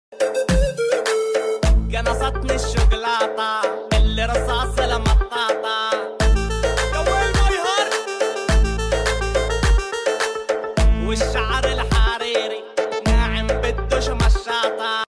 Arap şarkıları Fazla Güzel Sound Effects Free Download